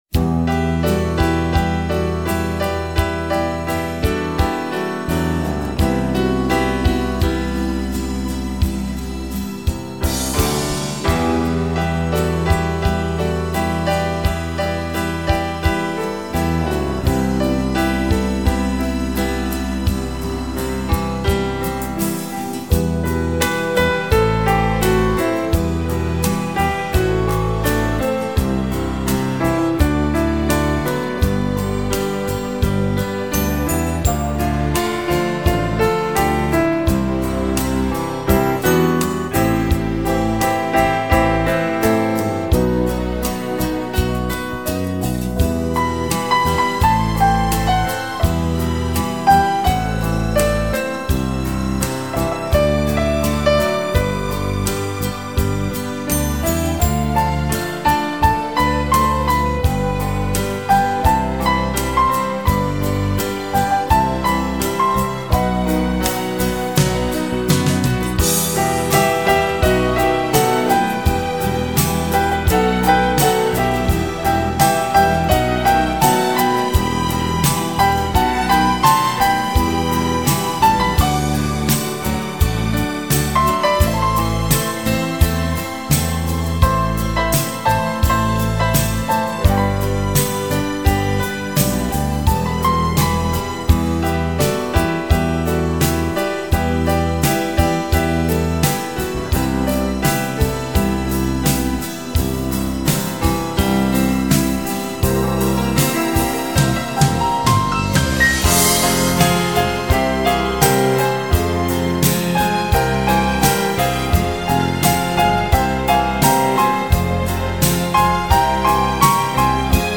Красивая мелодия
Для презентаций и релаксации